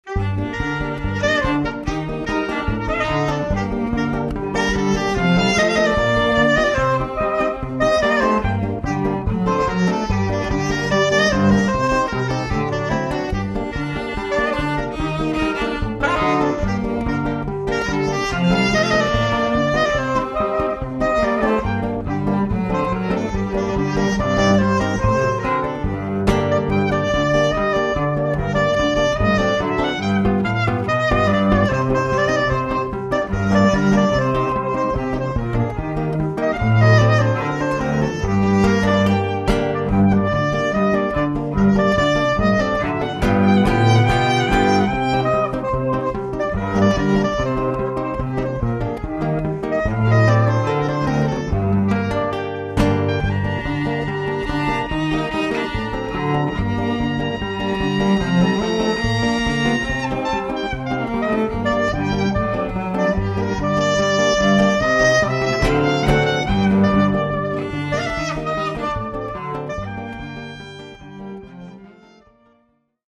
inem opgrund von der welt - lieder der osteuropaeischen juden ueber ihre verfolgung im deutschen nationalsozialismus